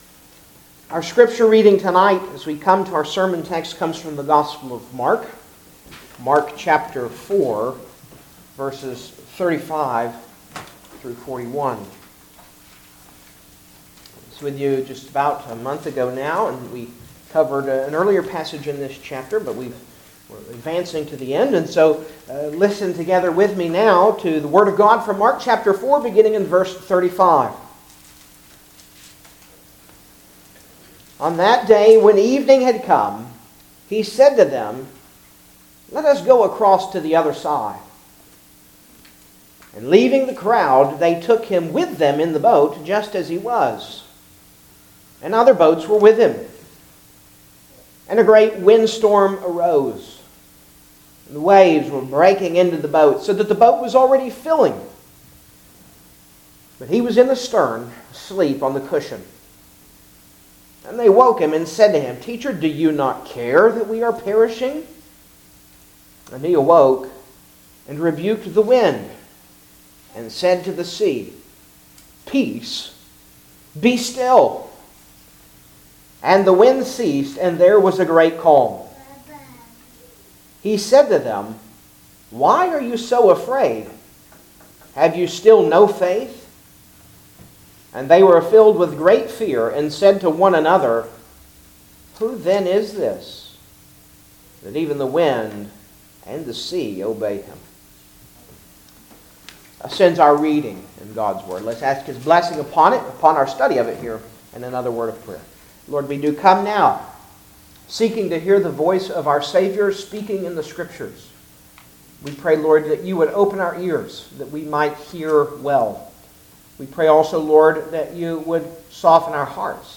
Gospel of Mark Passage: Mark 4:35-41 Service Type: Sunday Evening Service Download the order of worship here .